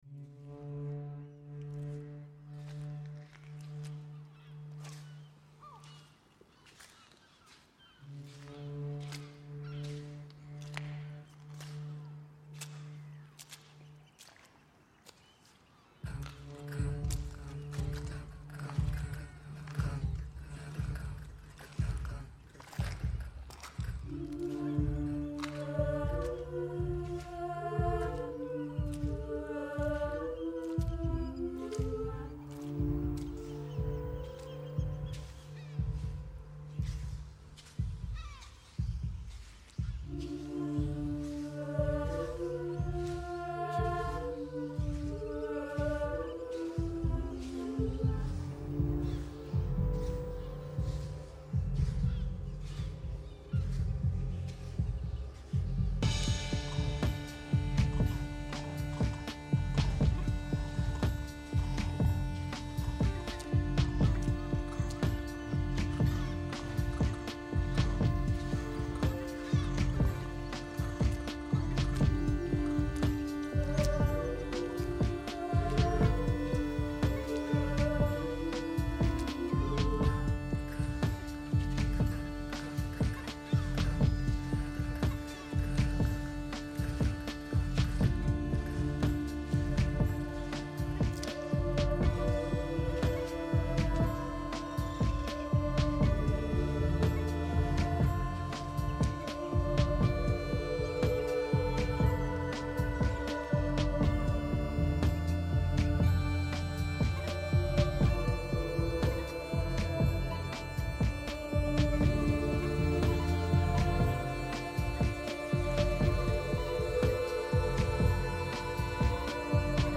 Portobello beach, Edinburgh reimagined